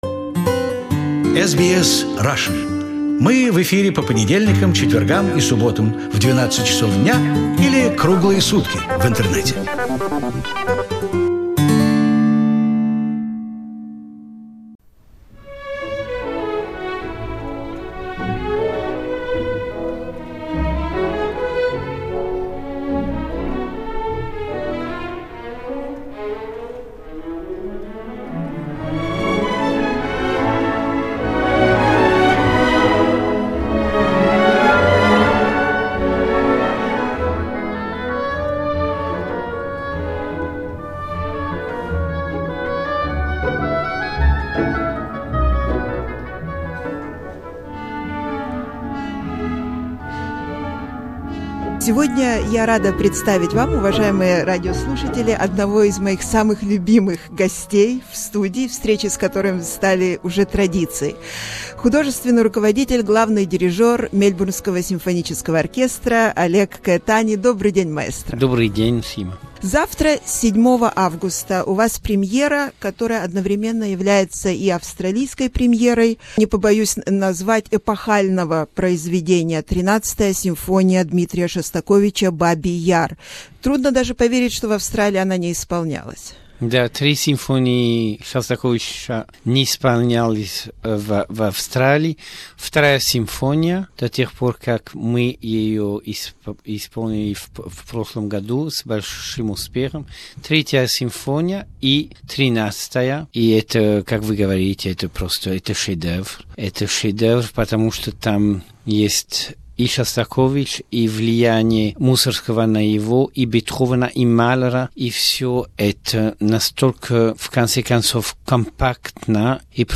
Musical conversations with maestro Oleg Caetani. Part 1